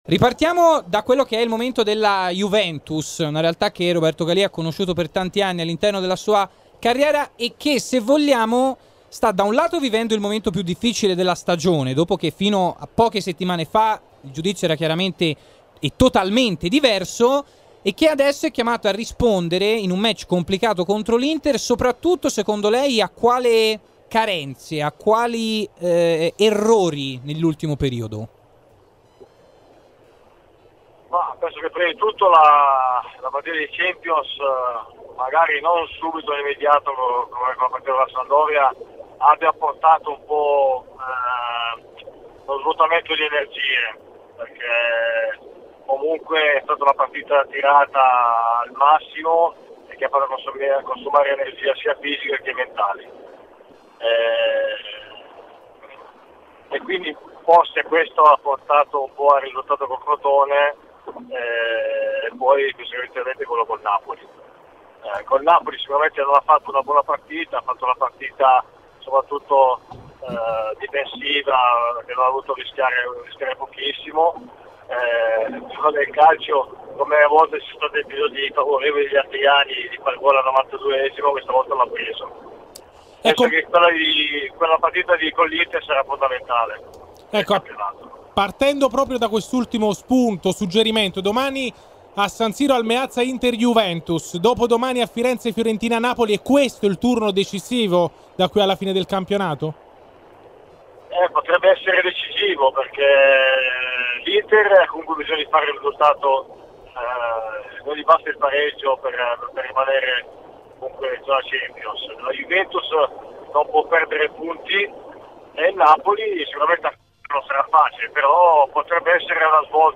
Roberto Galia, allenatore, sul momento della Juventus. In studio